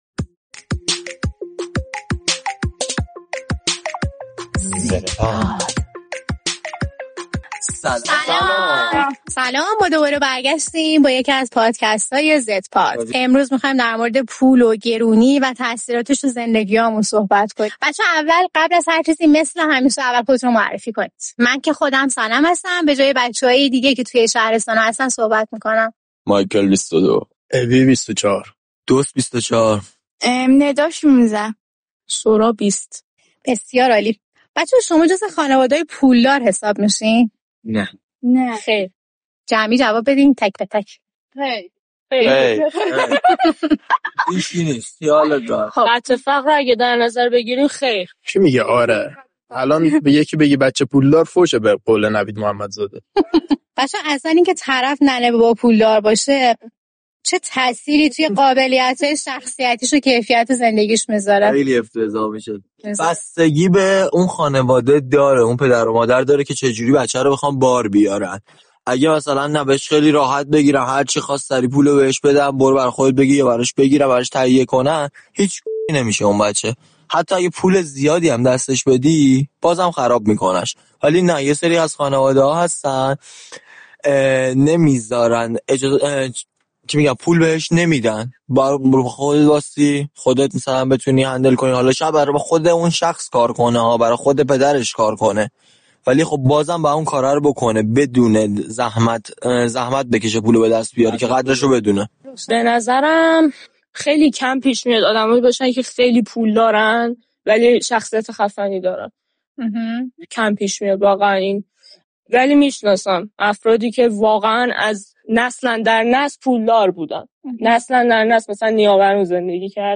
در این قسمت پادکست «زدپاد» گروهی از جوانان نسل زد درباره گرانی و تاثیر پول در زندگیشان گفت‌وگو می‌کنند.